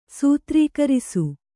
♪ sītrīkarisu